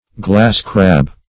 Glass-crab \Glass"-crab`\, n. (Zool.)